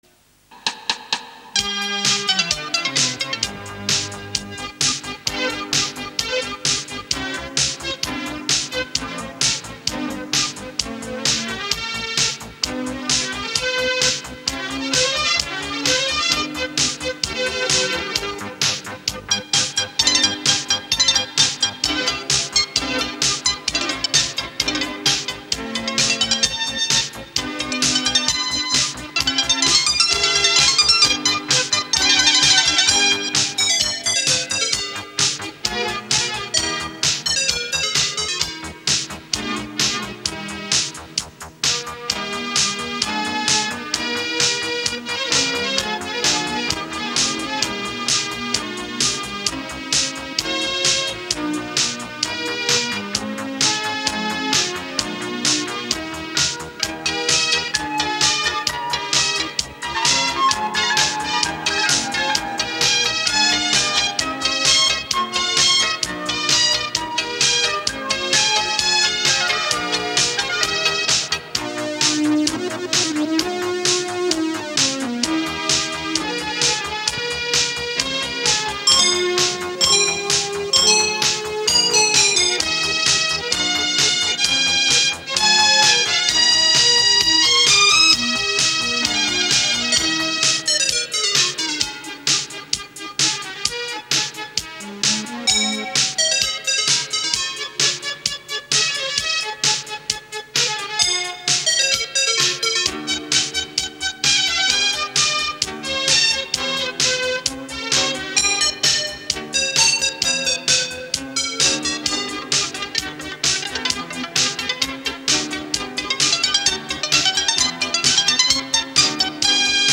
На касете звучит намного лучше, чем оцифровка.